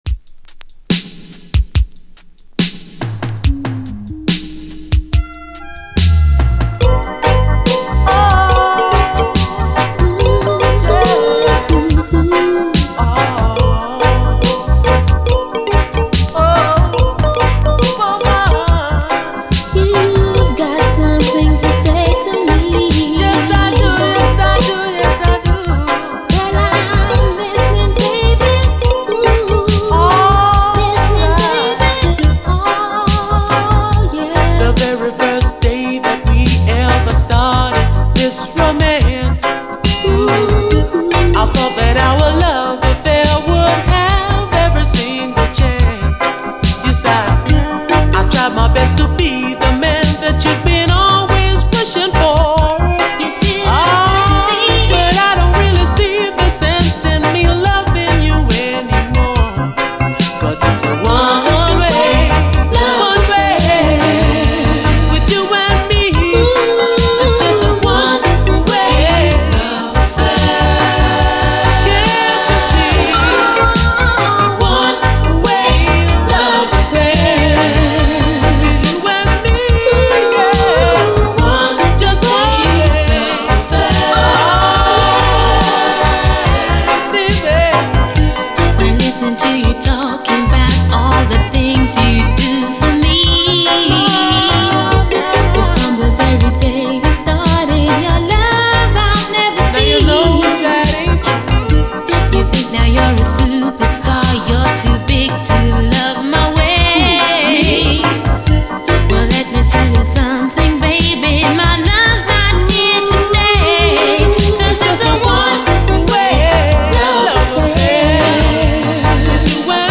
REGGAE
80’S胸キュンUKラヴァーズ！
リゾート感溢れるライトなサウンドも毎度素晴らしいです。インストも収録。